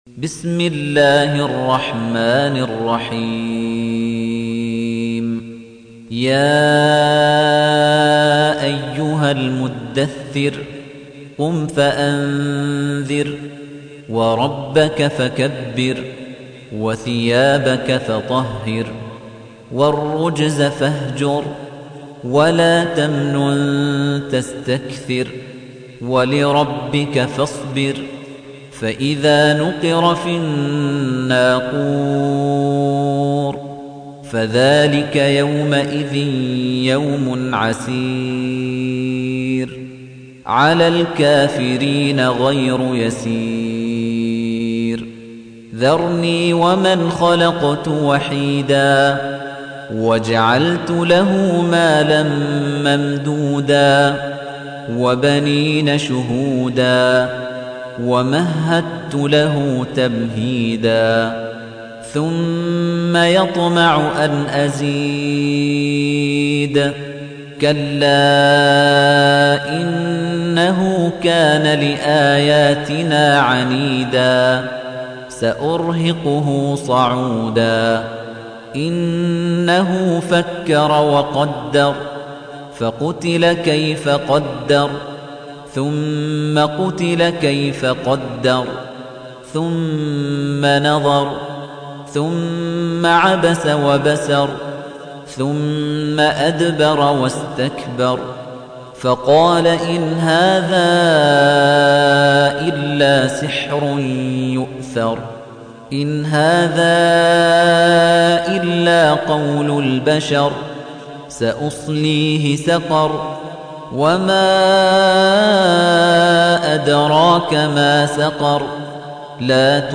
تحميل : 74. سورة المدثر / القارئ خليفة الطنيجي / القرآن الكريم / موقع يا حسين